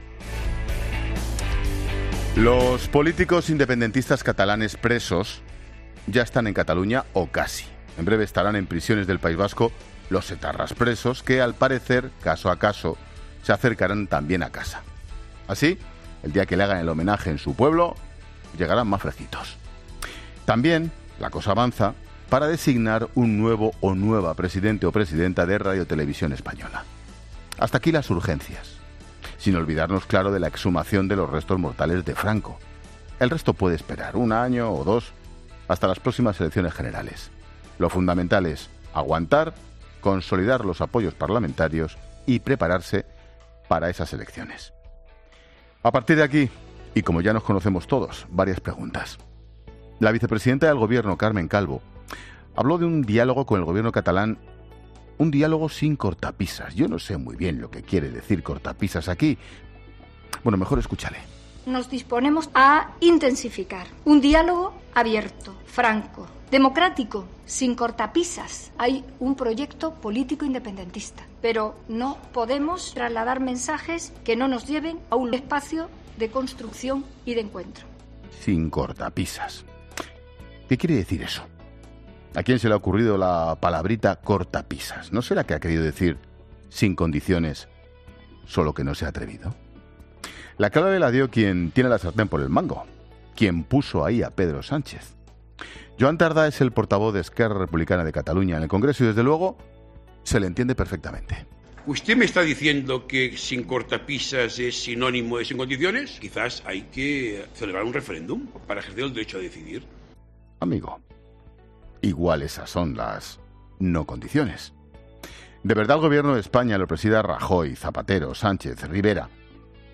Monólogo de Expósito
Comentario de Ángel Expósito sobre los cambios en RTVE.